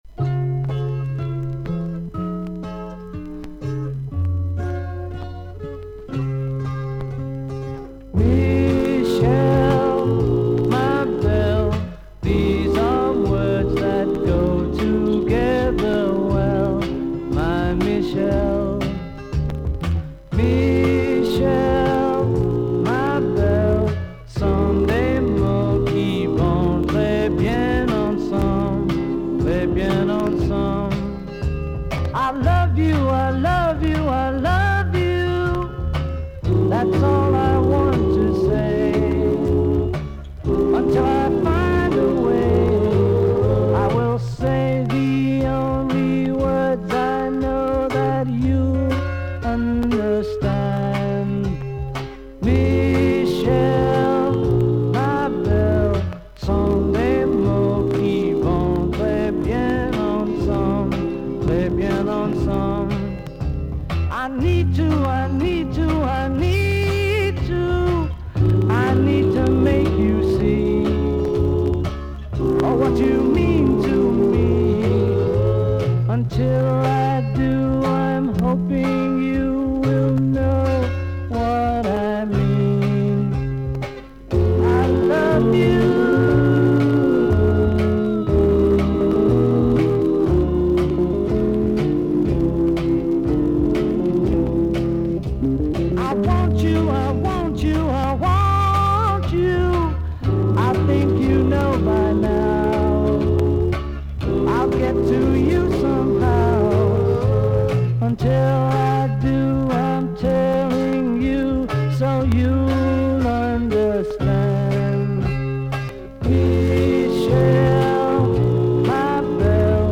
A5とA6はそれほどノイズが目立たないのですが、A7は周回ノイズがあります。
少々サーフィス・ノイズあり。クリアな音です。